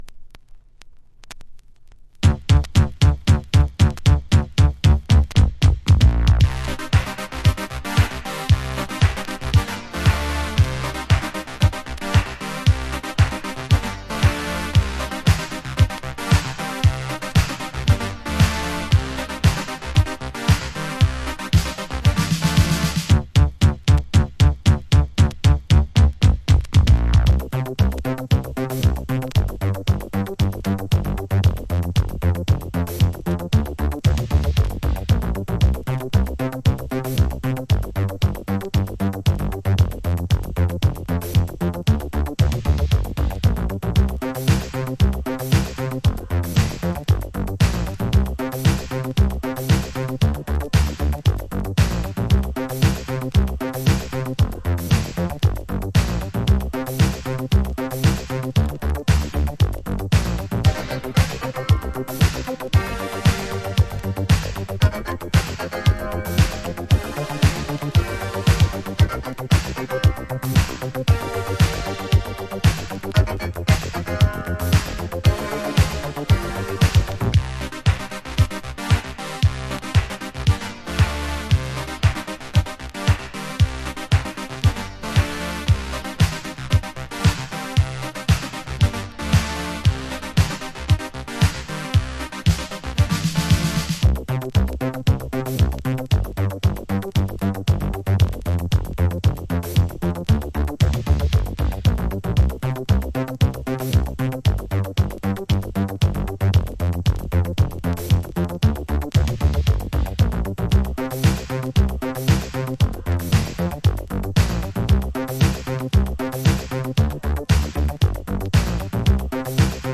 House / Techno
パワフルなディスコハウス。